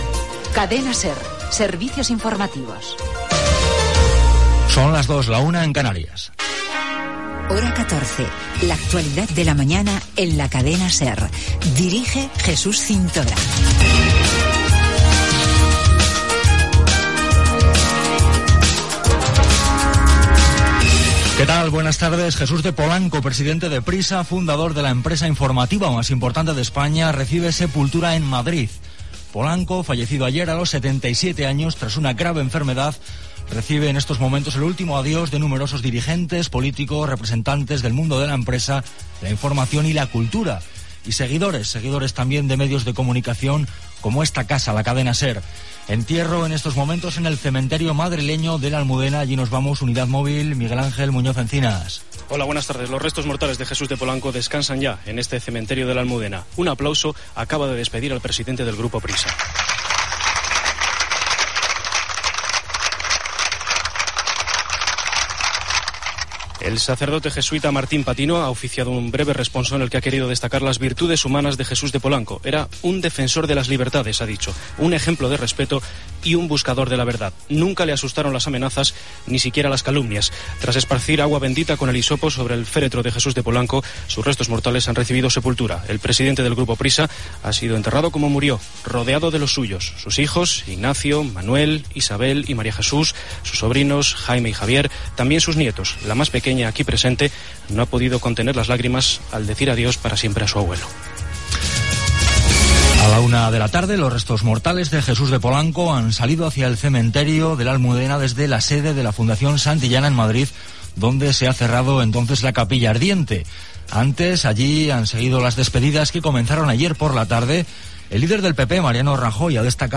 Careta del programa, enterrament de Jesús de Polando fundador i president del Grupo Prisa (declaracions del preident del Partido Popular, Mariano Rajoy, el ministre de Sanitat Bernat Soria, i Adolfo Suárez Illana), data, tiluars, resultats dels sortejos, el temps
Informatiu